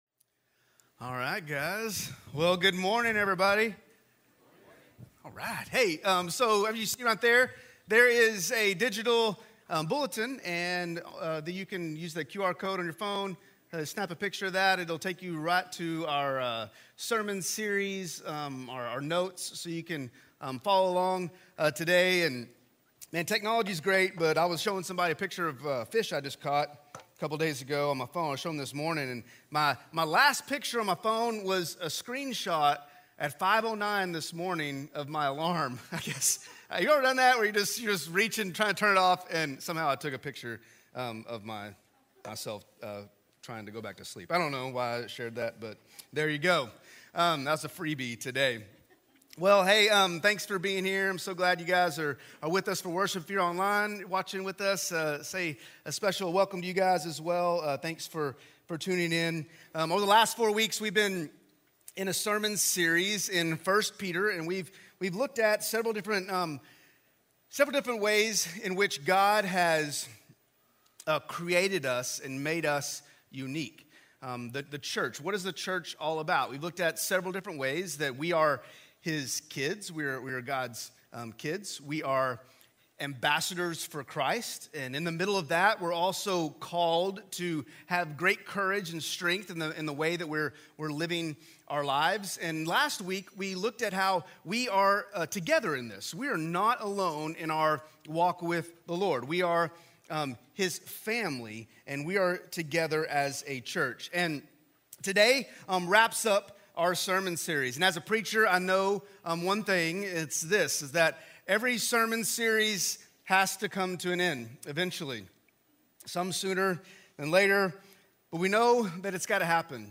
Message: “We Are…